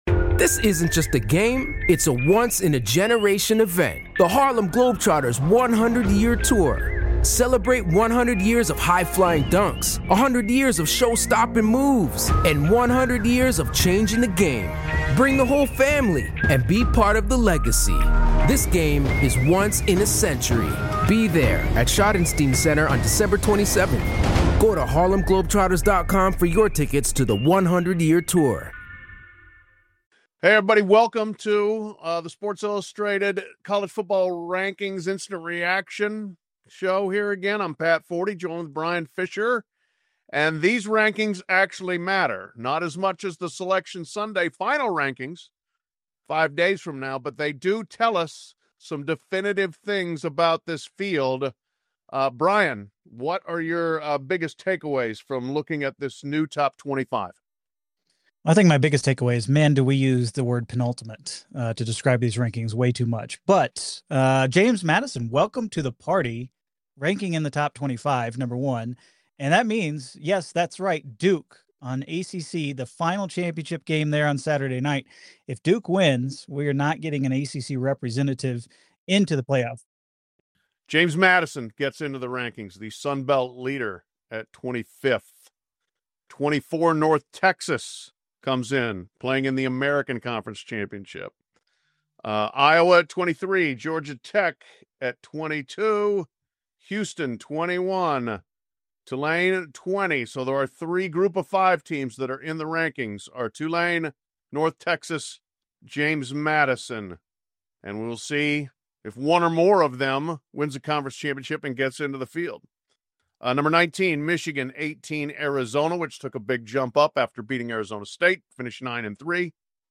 answer viewer questions and react live to the newest CFP Rankings on Tuesday, December 2nd!